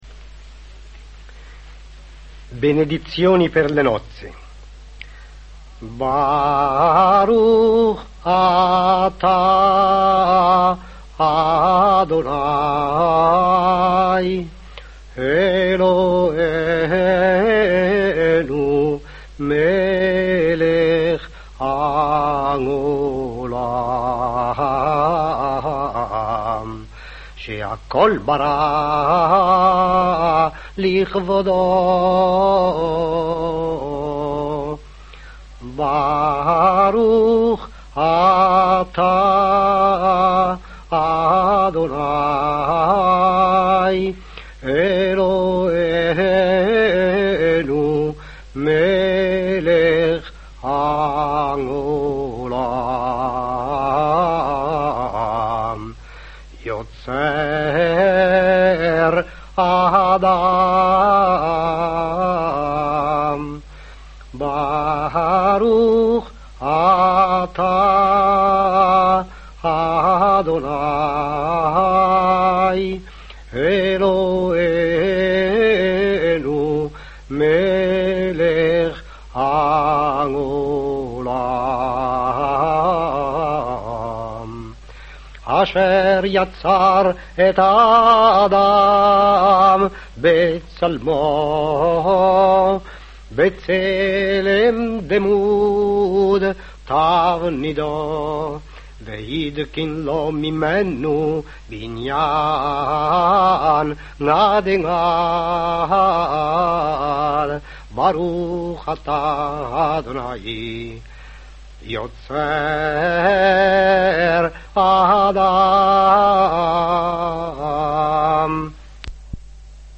sefardita